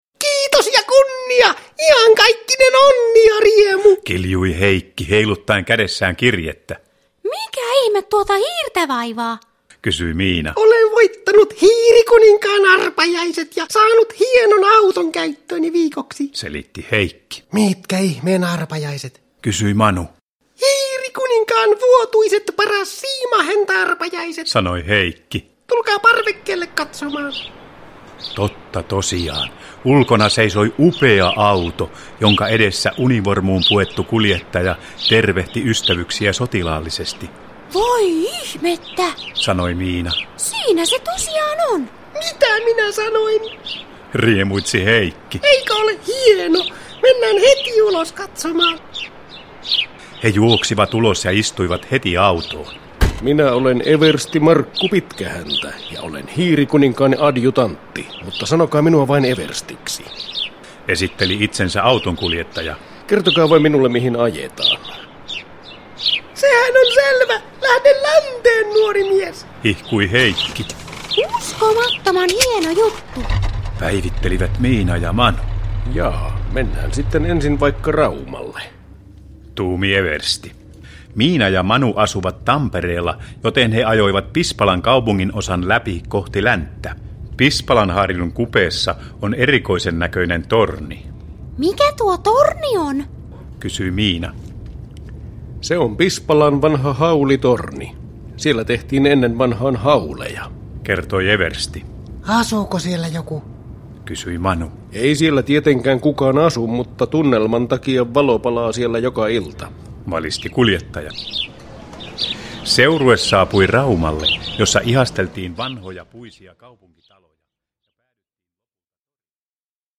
Miinan ja Manun seikkailu Suomessa – Ljudbok – Laddas ner